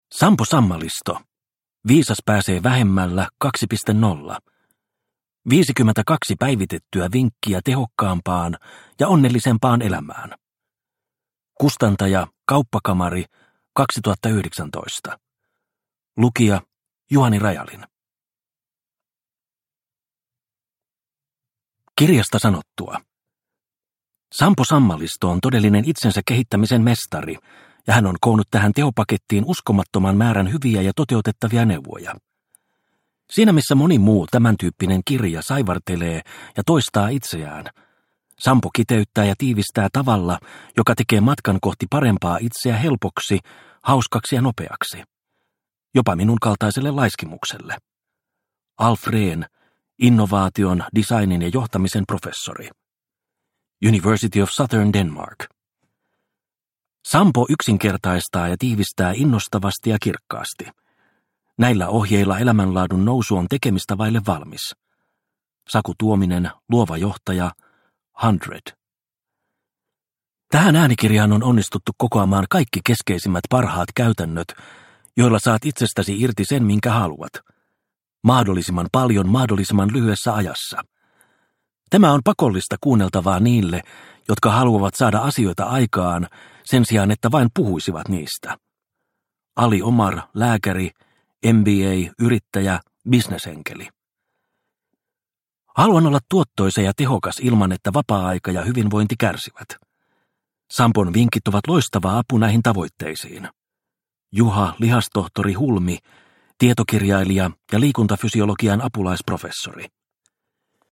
Viisas pääsee vähemmällä 2.0 – Ljudbok – Laddas ner